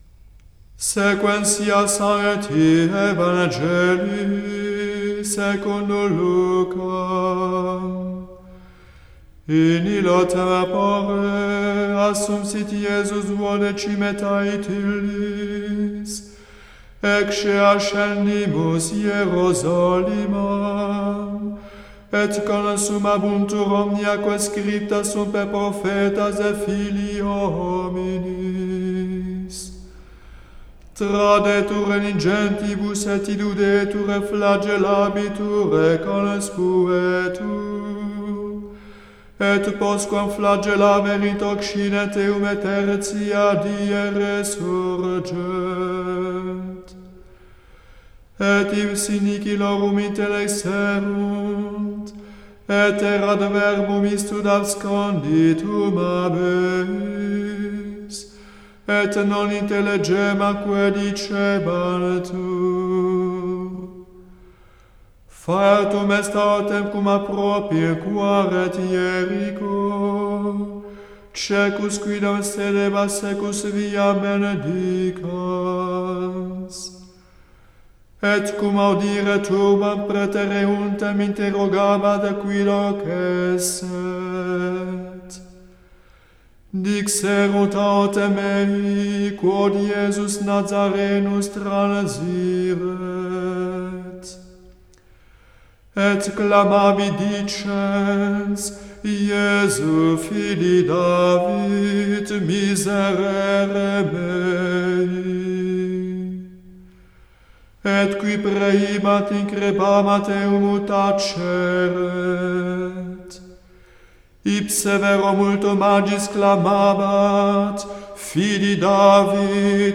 Evangile du dimanche de la Quinquag�sime NB.